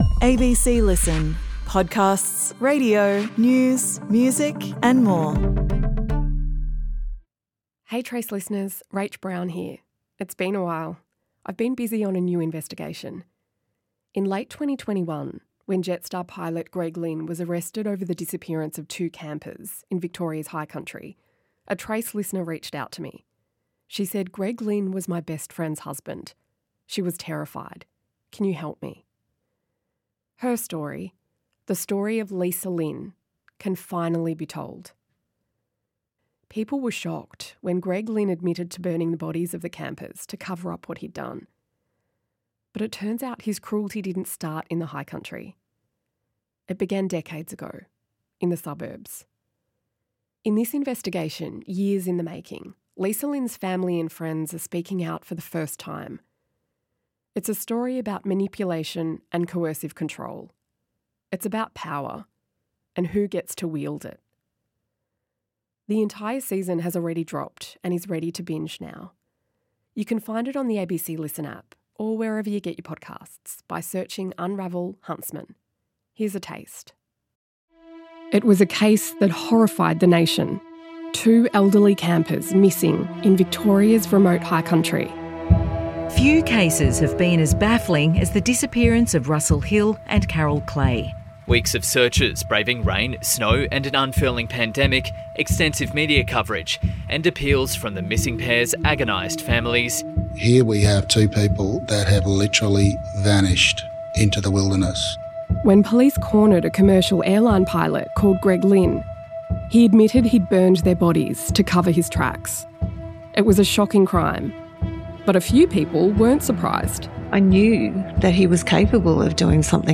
… continue reading 97 episodes # Storytelling # Binge-Worthy True Crime # Binge-Worthy Documentary # Australia # True Crime # Society # Documentaries # ABC Radio